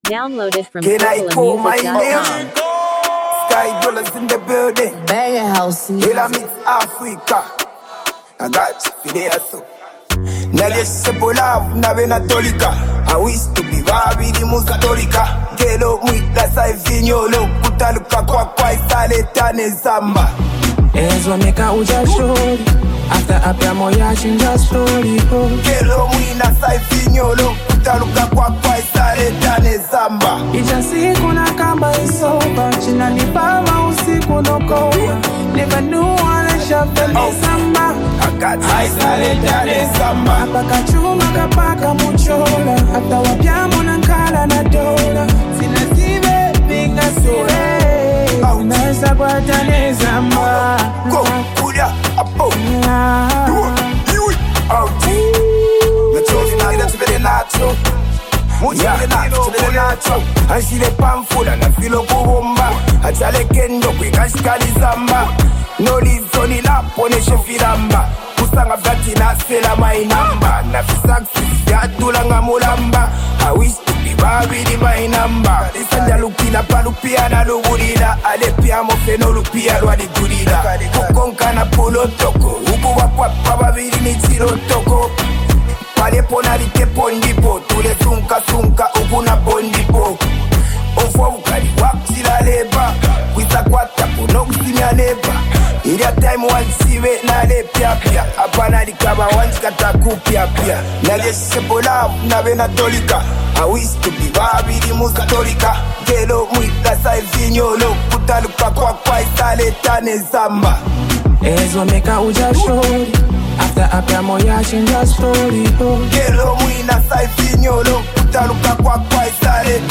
a powerful and uplifting song